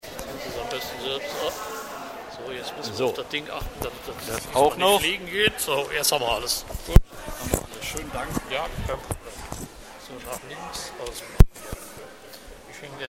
FAUST-Programm, Hochschule Niederrhein, WS 2021/22, 8 Vorlesungen